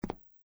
普通的行走脚步单生－左声道－YS070525.mp3
通用动作/01人物/01移动状态/普通的行走脚步单生－左声道－YS070525.mp3